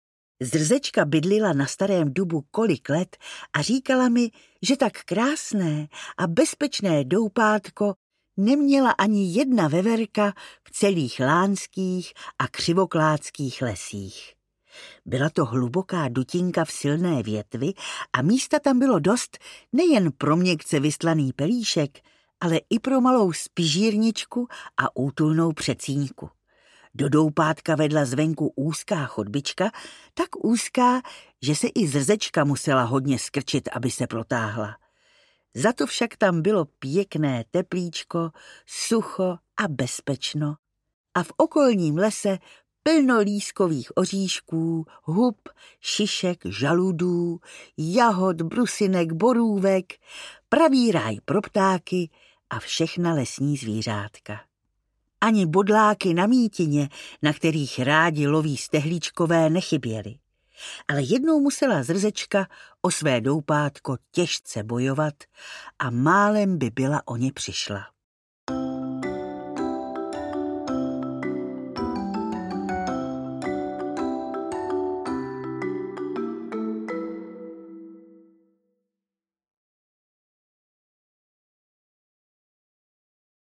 Dobrodružství veverky Zrzečky audiokniha
Ukázka z knihy
Čte Naďa Konvalinková.
• InterpretNaďa Konvalinková